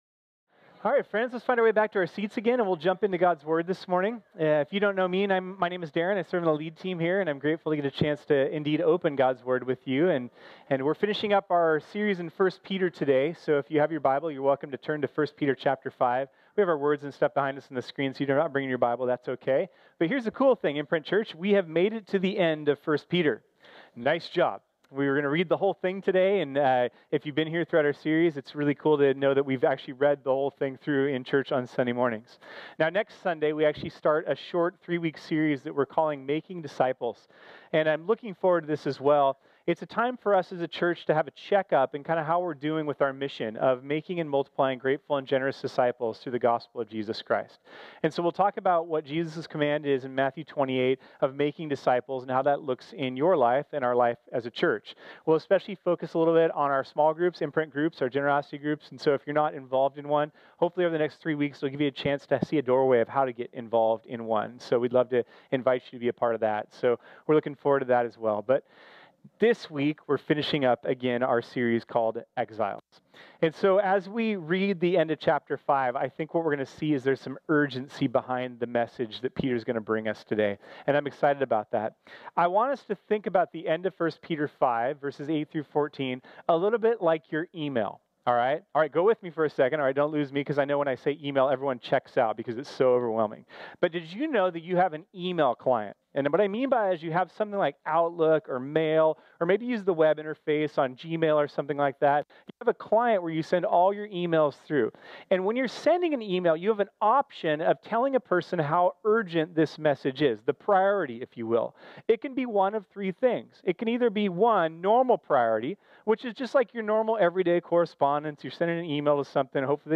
This sermon was originally preached on Sunday, April 29, 2018.